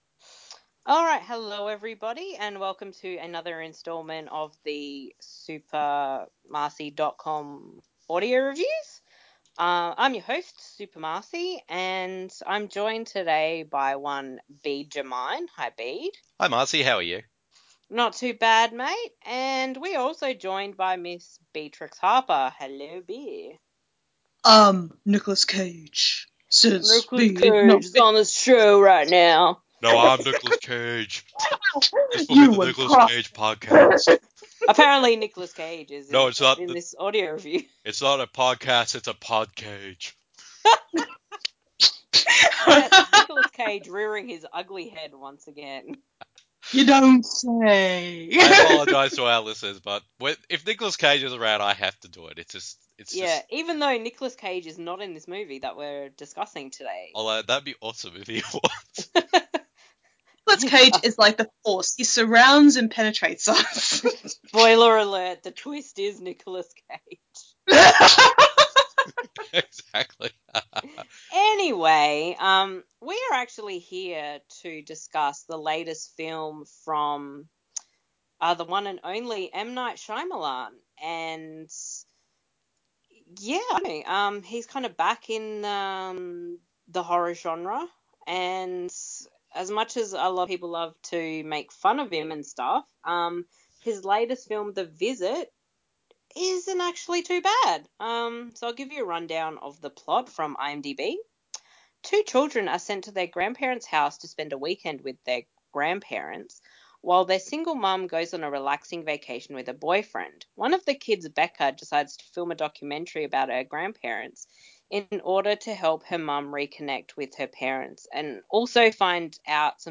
The following review in an audio format, as a back and forth discussion between the three of us.